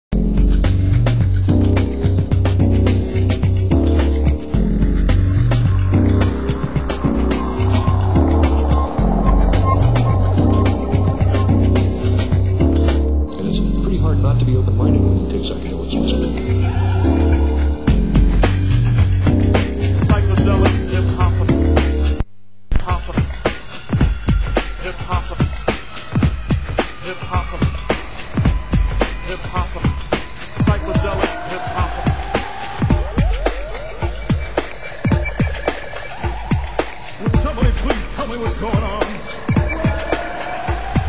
Format: Vinyl 12 Inch
Genre: Hip Hop